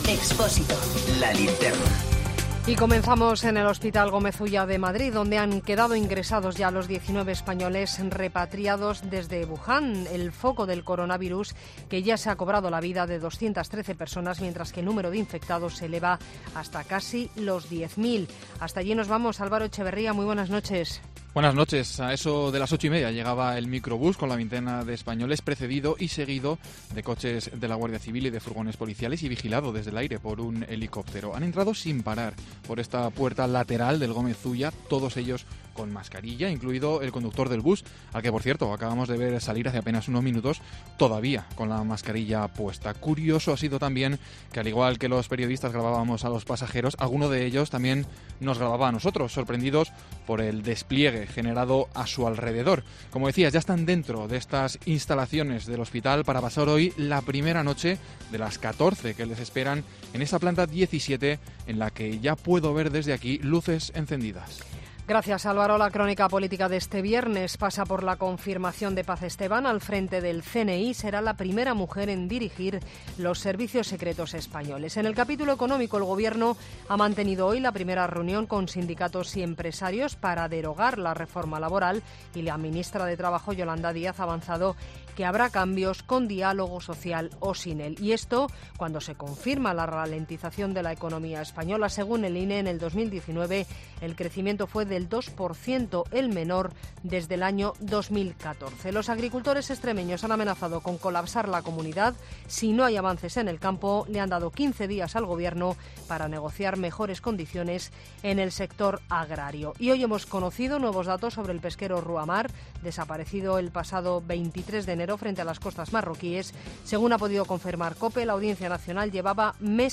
Boletín de noticias COPE del 31 de enero de 2020 a las 21.00 horas